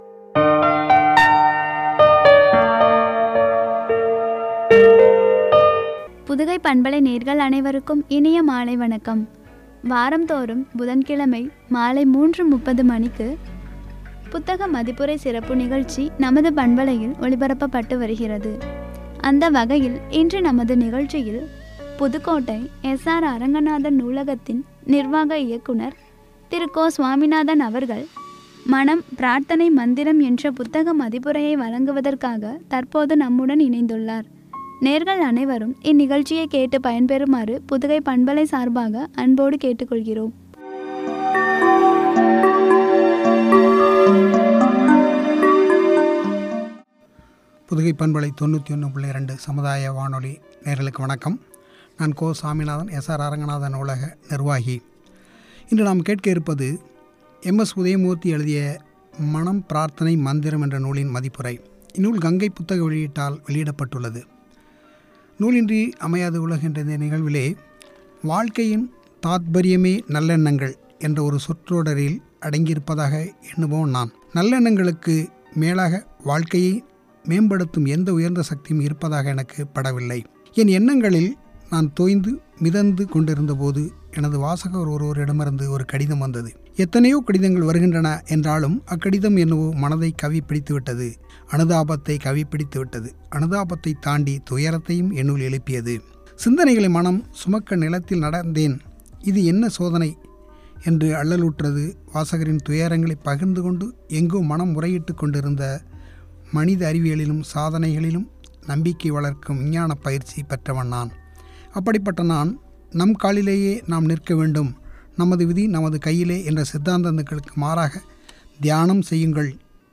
மந்திரம்” புத்தக மதிப்புரை